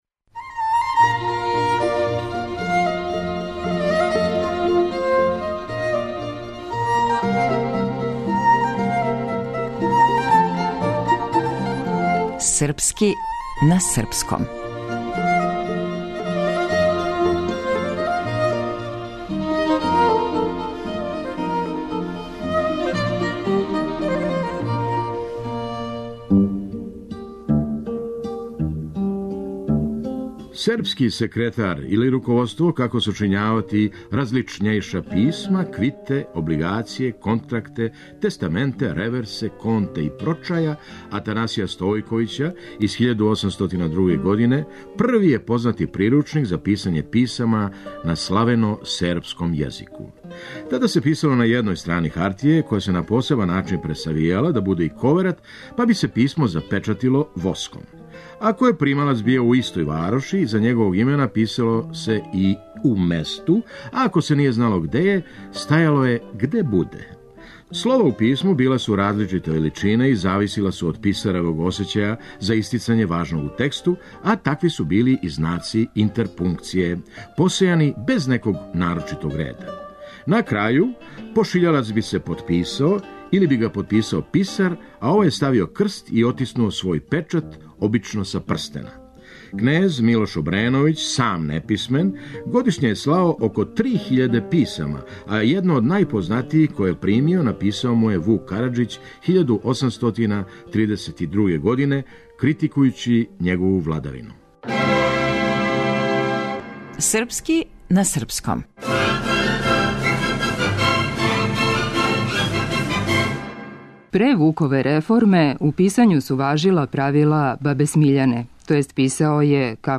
Драмски уметник: Феђа Стојановић